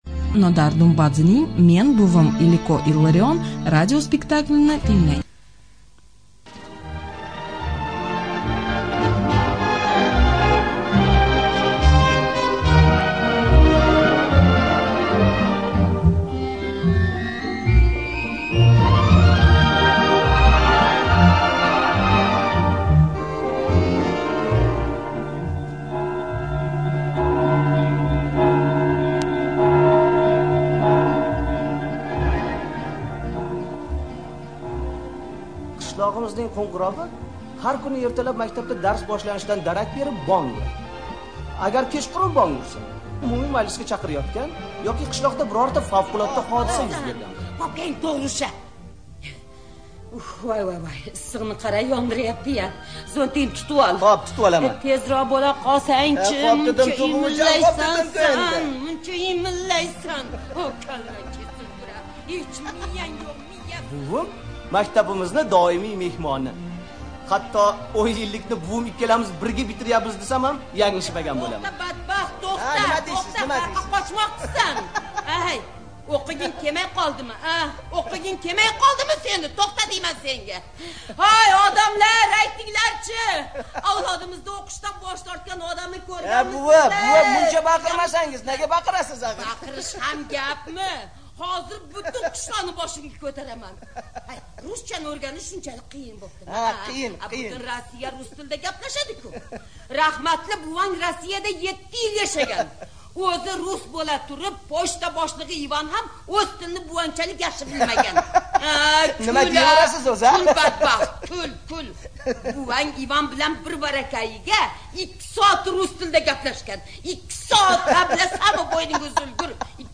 ЖанрРадиоспектакли на узбекском языке